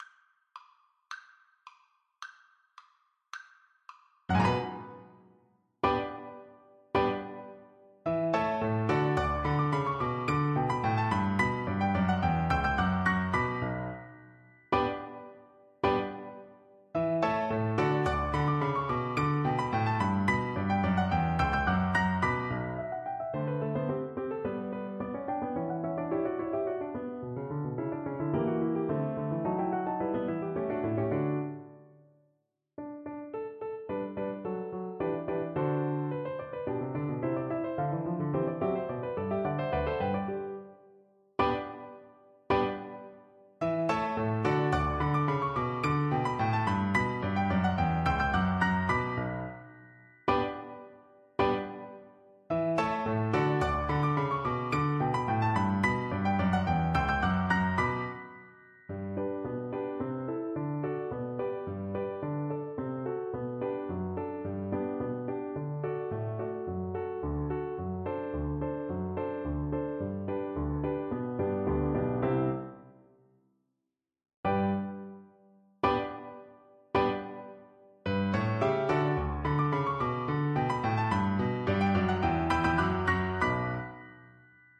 Play (or use space bar on your keyboard) Pause Music Playalong - Piano Accompaniment Playalong Band Accompaniment not yet available transpose reset tempo print settings full screen
Violin
B minor (Sounding Pitch) (View more B minor Music for Violin )
2/2 (View more 2/2 Music)
Allegro ridicolo = c. 108 (View more music marked Allegro)
Classical (View more Classical Violin Music)